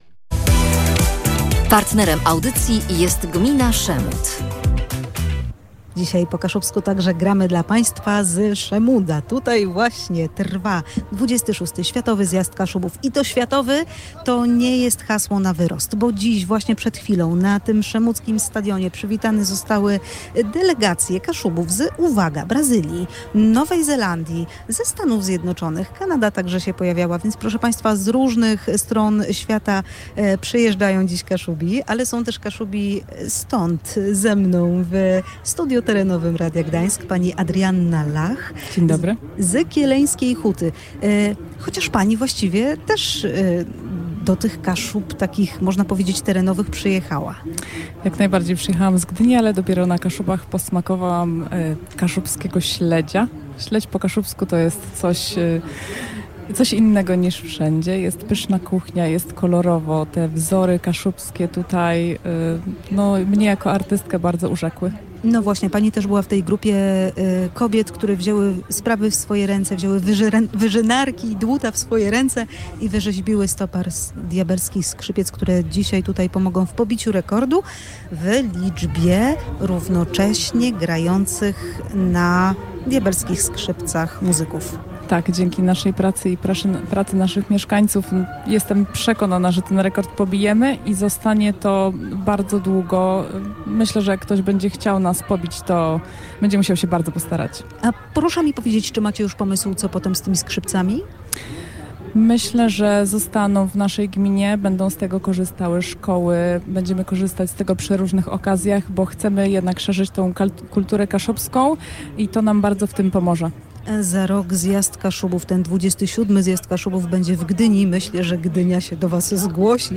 Radio Gdańsk na XXVI Światowym Zjeździe Kaszubów w Szemudzie. Nadawaliśmy na żywo z serca Kaszub